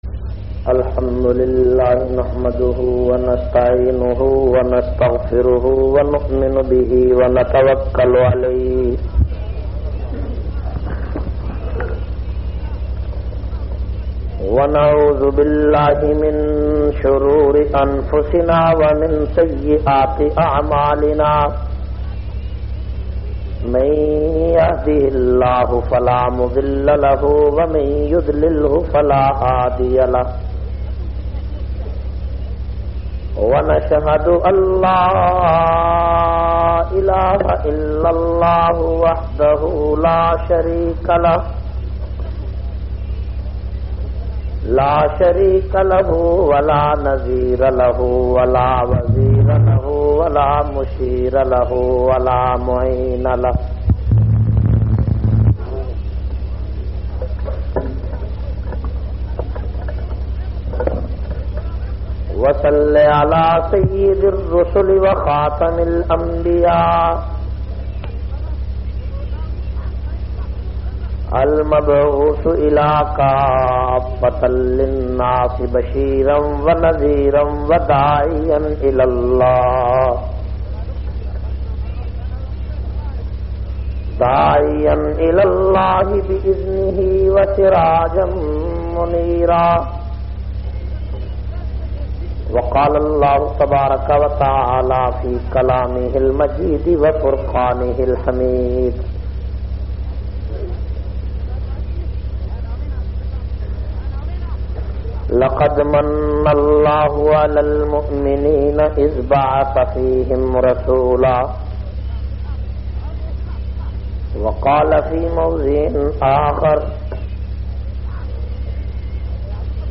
679- Saqi e Kausar Conference-JaranWala.mp3